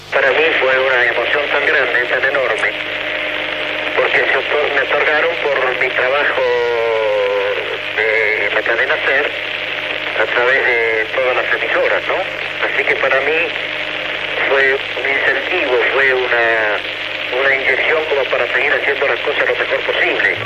Paraules de Pepe Iglesias "El zorro" guanyador d'un Premio Ondas l'any 1954
Fragment extret del programa "La radio con botas", emès per Radio 5 l'any 1991